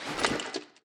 equip_iron6.ogg